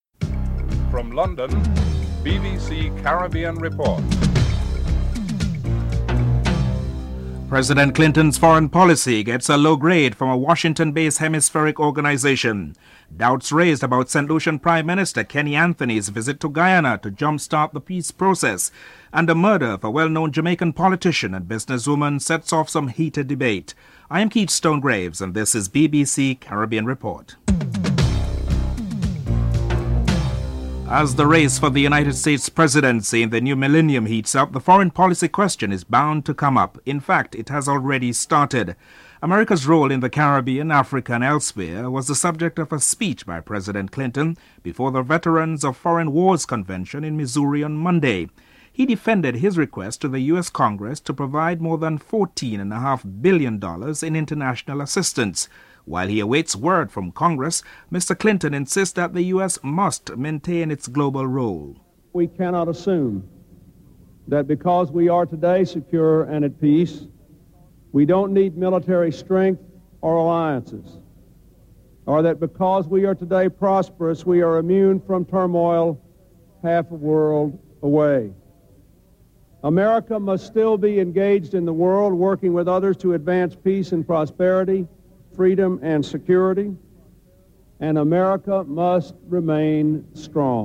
1. Headlines
The role of the US in Africa, Caribbean and other nation states is one of the significant talking points in the upcoming US general elections. US President Bill Clinton in a speech before the Veteran of Foreign Wars Convention in Missouri defends his request to Congress to provide 14 billion US dollars in international assistance to these countries.